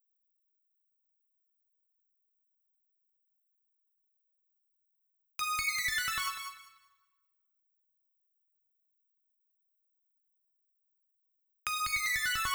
シンセリードパート
ブラスのロングトーンで顔を出すシンセリードです。
暫く無音が続きますが、ブラスの切れ目に入ってくるキラキラした音がそれです。